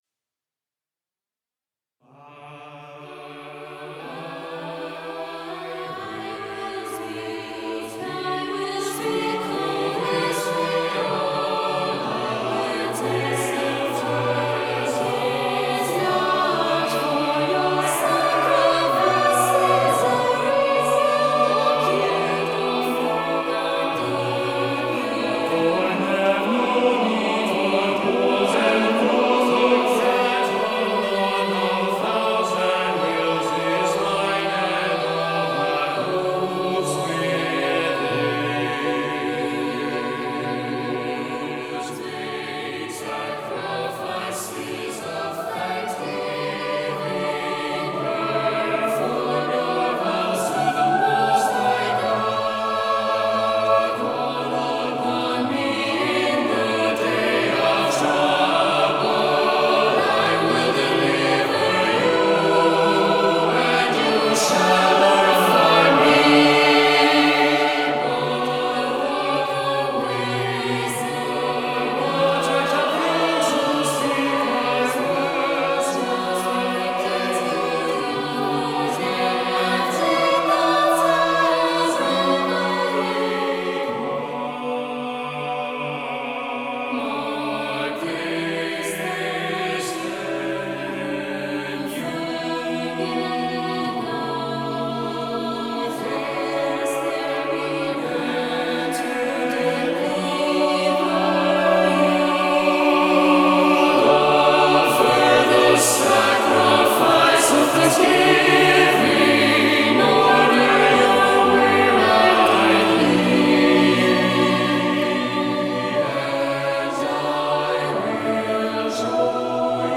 This choral piece is written for an SATB choir to be sung acapella.